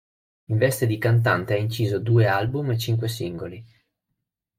Pronounced as (IPA) /ˈvɛs.te/